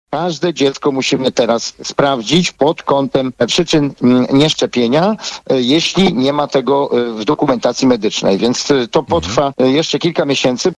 Jak tłumaczy Główny Inspektor Sanitarny, Paweł Grzesiowski, chodzi głównie o dzieci, które z różnych powodów nie zostały ujęte w papierowych rejestrach.